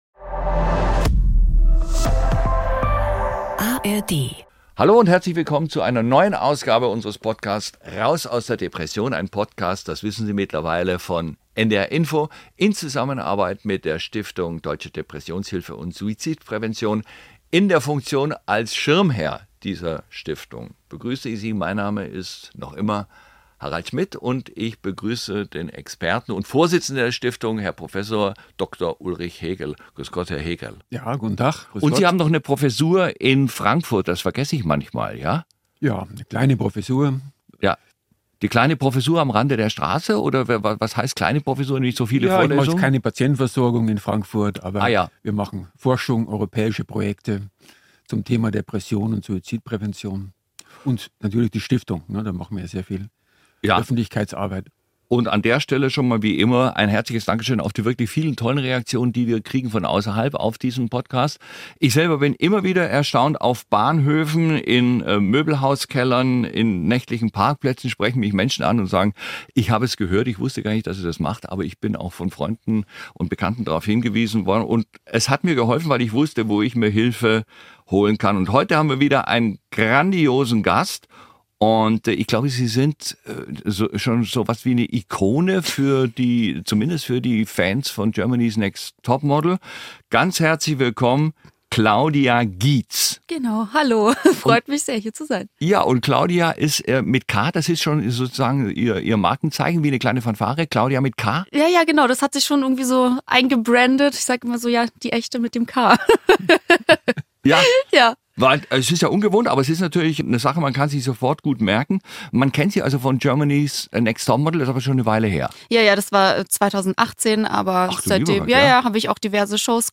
Klaudia Giez berichtet im Gespräch mit Host Harald Schmidt, dass sie sich daraufhin selbst in die Klinik eingewiesen hat.